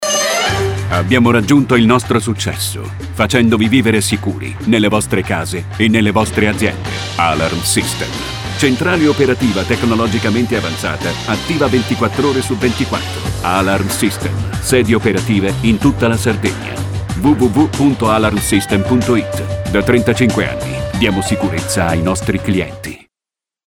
SPOT ISTITUZIONALE